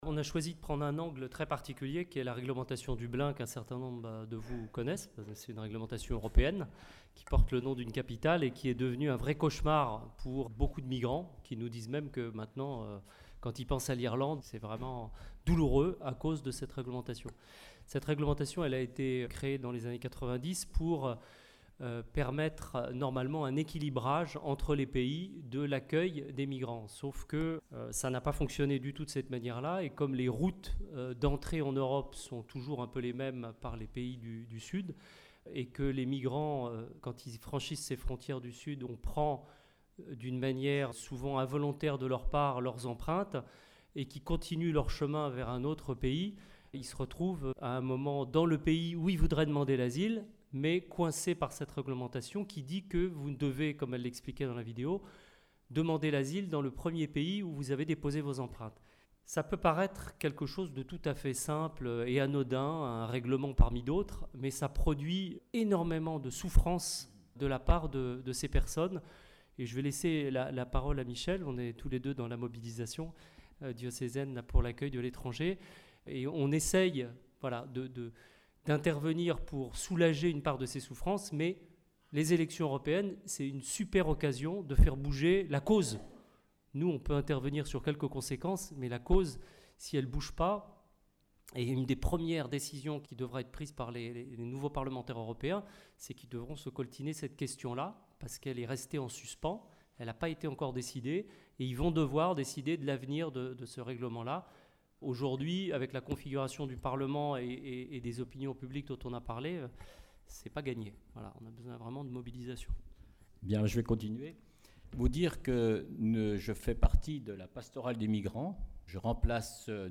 Soirée 'Europe' du 6 mai au Centre diocésain - témoignages et questions